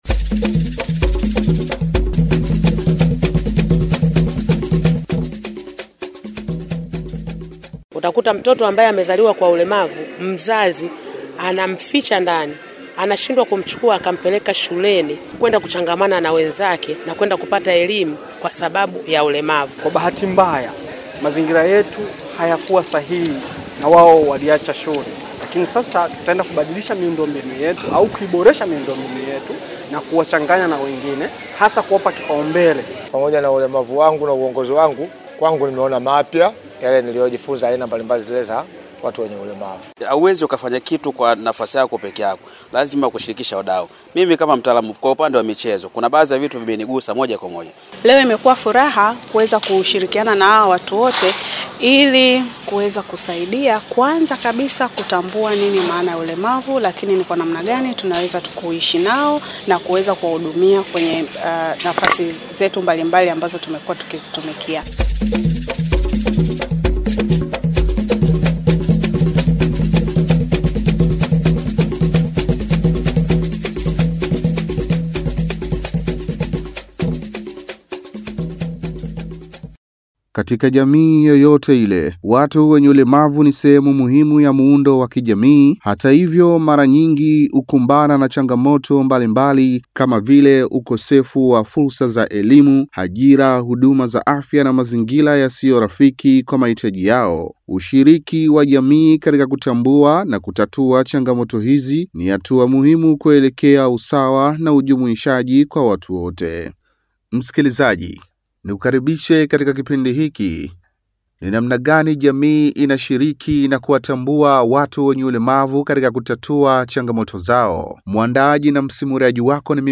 Makala